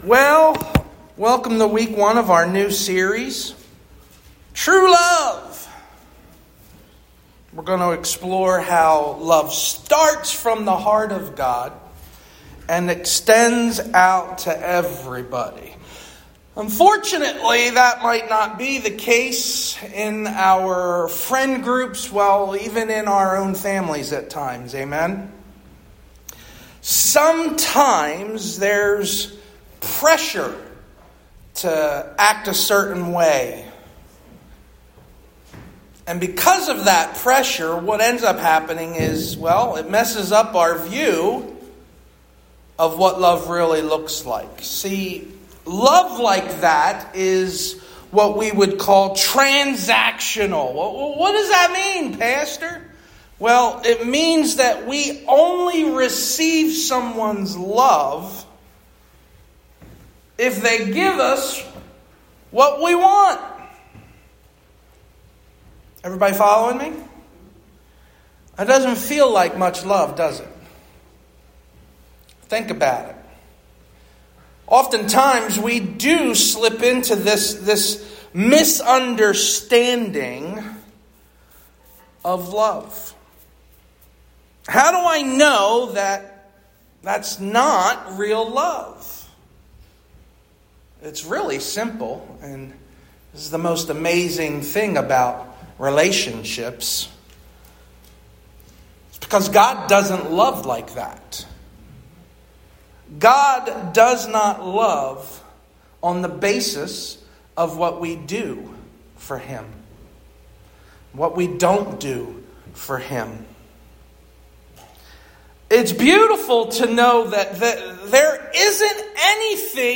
Our Sermon Series